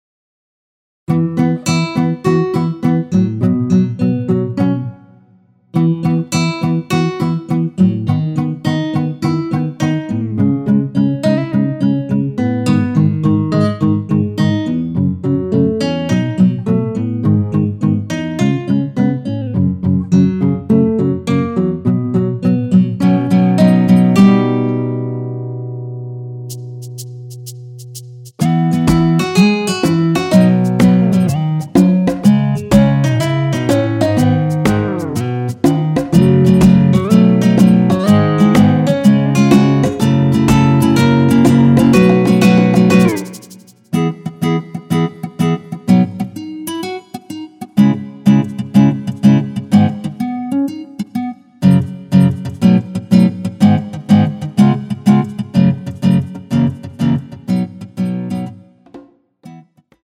MR입니다.
전주 없이 시작 하는곡이라 노래 하시기 편하게 전주 2마디 많들어 놓았습니다.(미리듣기 확인)
앞부분30초, 뒷부분30초씩 편집해서 올려 드리고 있습니다.
중간에 음이 끈어지고 다시 나오는 이유는